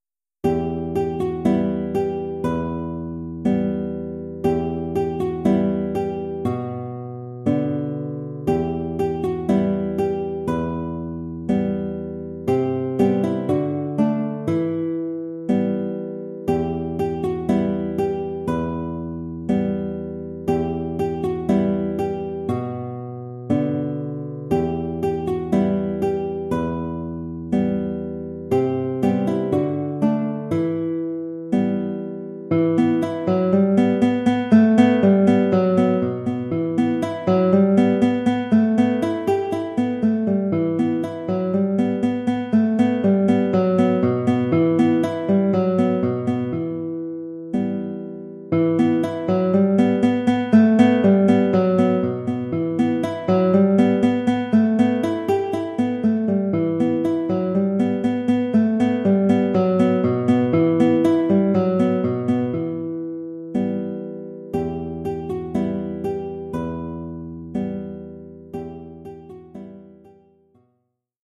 Genre musical : Classique
Formule instrumentale : Guitare solo
Oeuvre pour guitare solo.